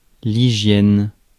Ääntäminen
IPA : /ˈhaɪdʒiːn/